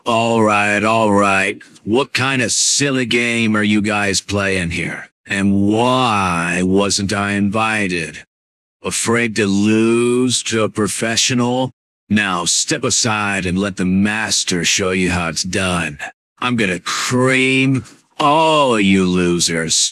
Voice Cloning
ProtomanSample.wav